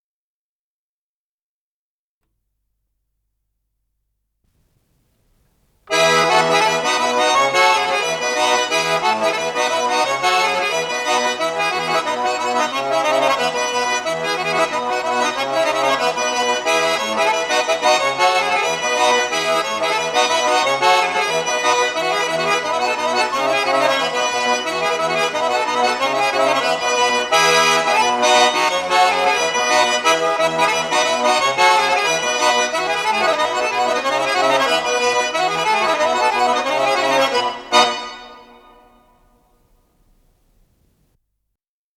с профессиональной магнитной ленты
гармонь - сибирская однорядка
ВариантДубль моно